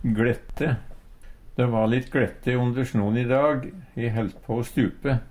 Høyr på uttala Ordklasse: Adjektiv Kategori: Vêr og føre Attende til søk